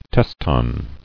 [tes·ton]